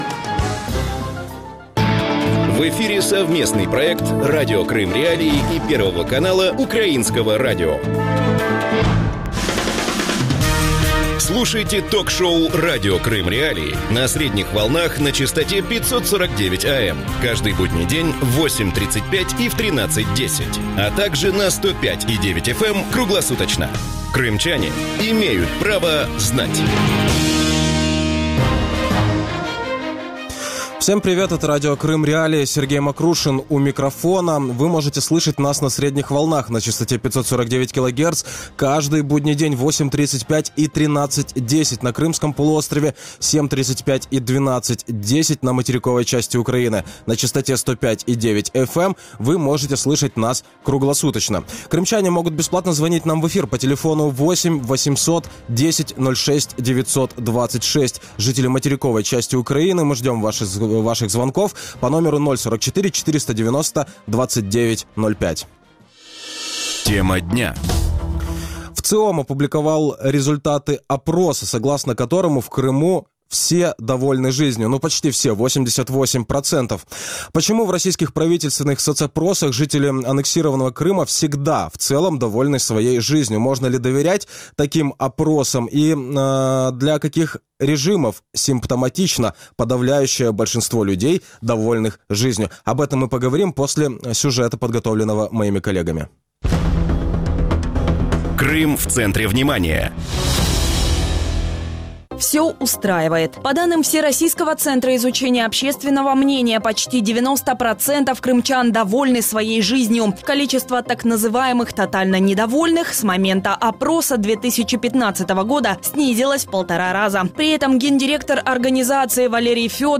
Можно ли доверять опросам, которые проводятся на оккупированной территории? Гости эфира